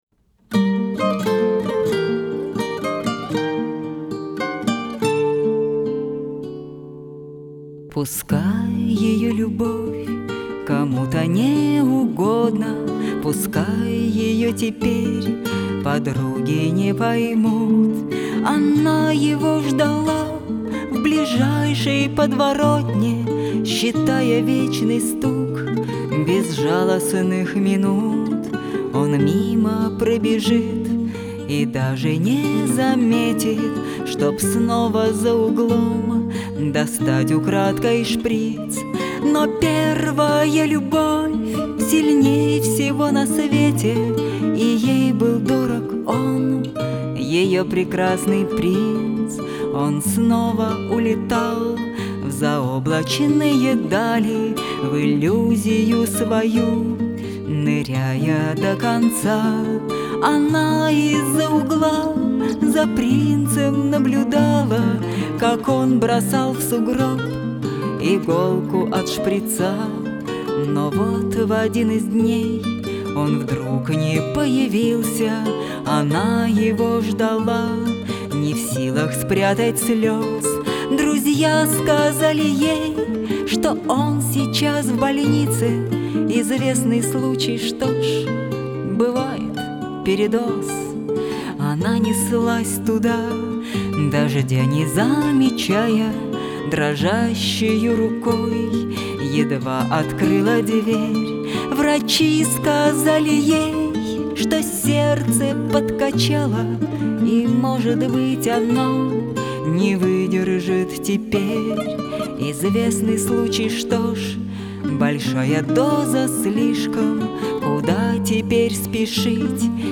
гитара, мандолина.